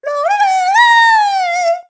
One of Honey Queen's voice clips in Mario Kart 7